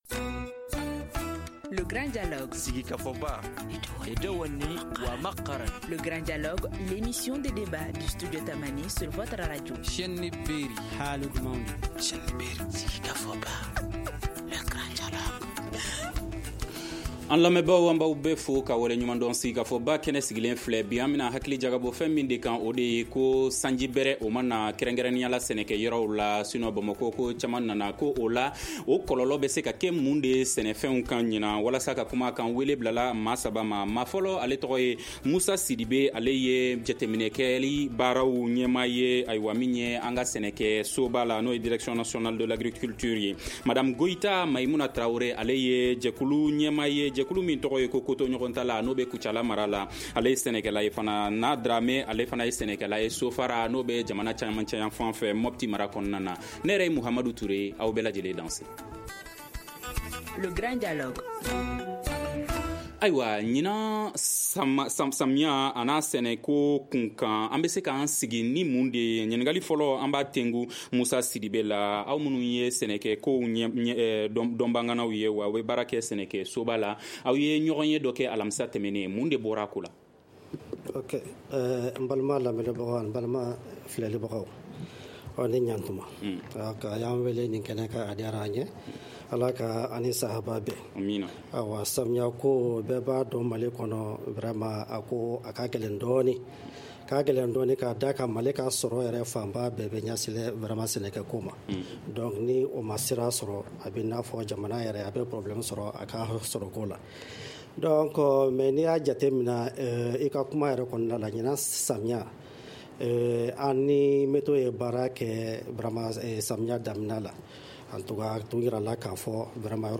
Le Grand Dialogue pose le débat.